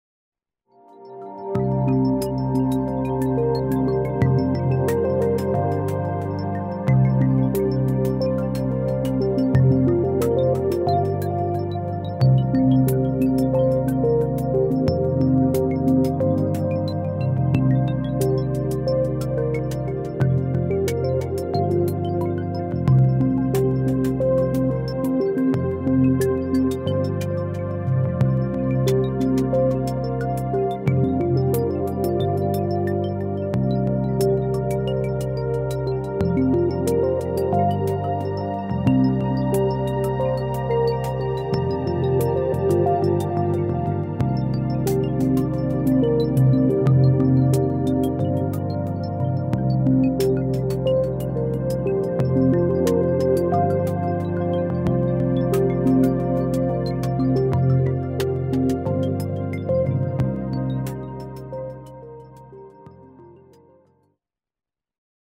Ambient Electronic Soundscapes
electronic music with sequences and rhythms